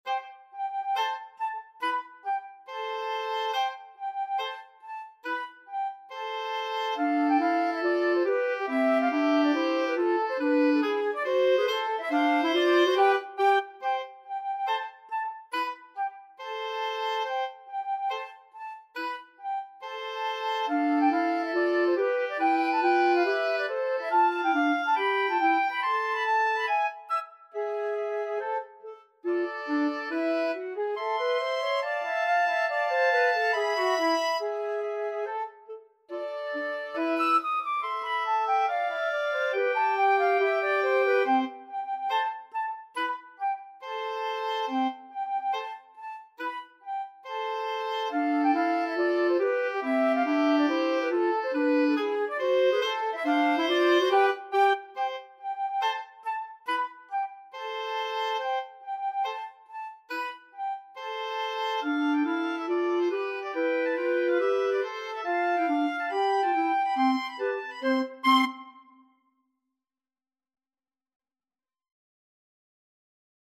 4/4 (View more 4/4 Music)
Tempo di marcia =140
Classical (View more Classical Flute-Oboe-Clarinet Music)